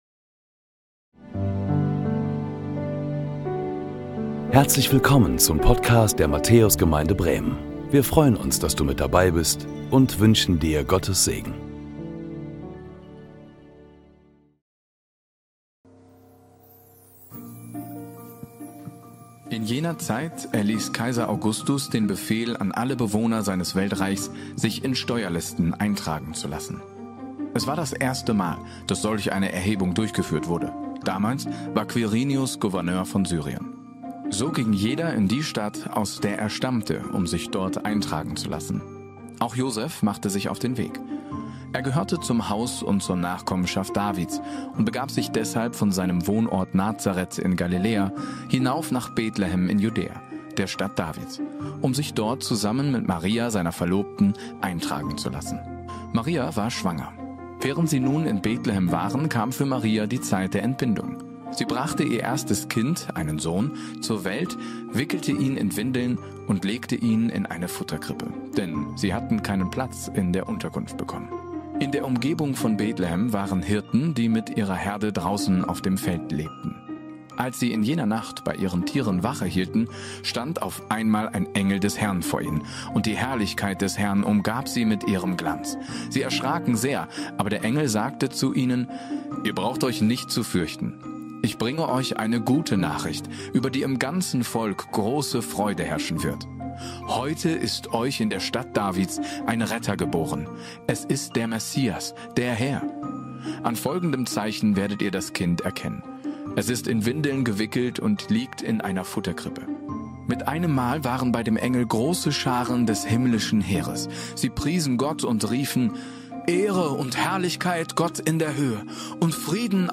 Wir übertragen diesen Gottesdienst live aus Bremen-Huchting und wünschen uns, dass Gott Dir ganz nahe kommt in dieser Stunde, die Du mit uns am 24.12. (oder beim Nachschauen in den Tag...
Predigttext: Johannes 1, 14-16 Mehr